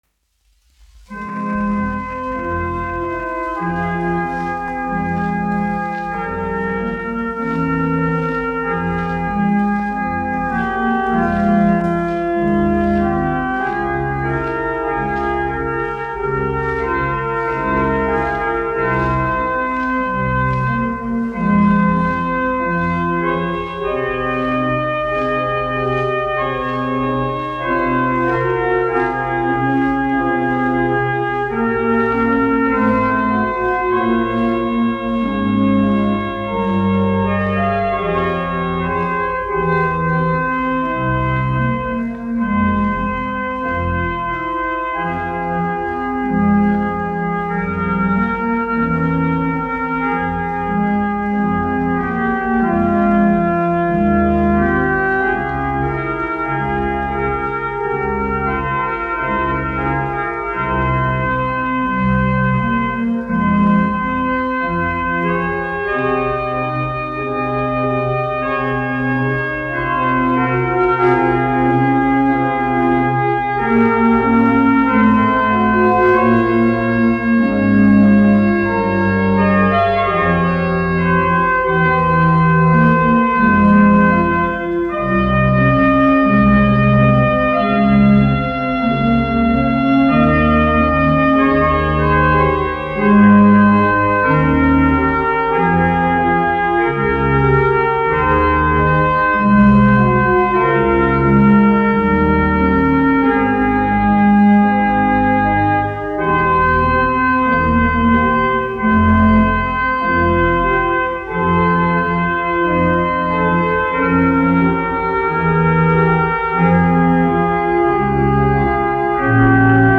1 skpl. : analogs, 78 apgr/min, mono ; 25 cm
Ērģeļu mūzika